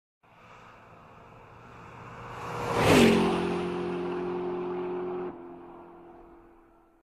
Car Passing 1.ogg